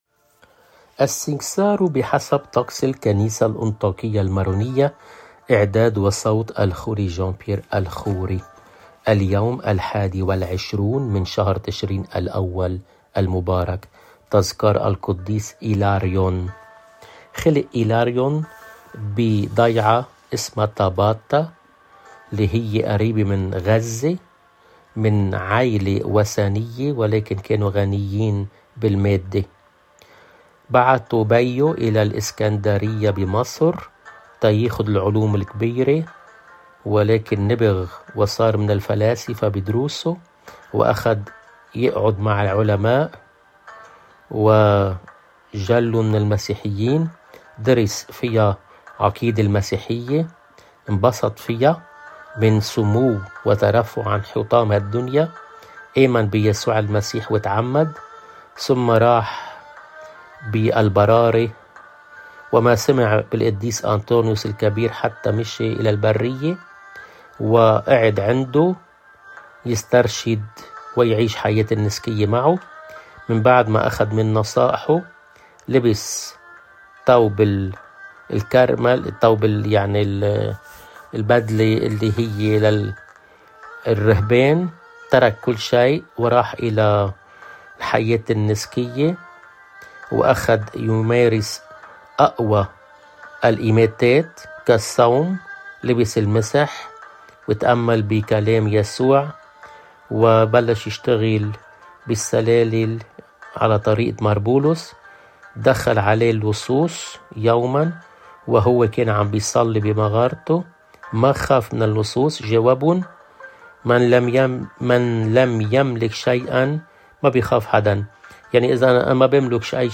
بصوت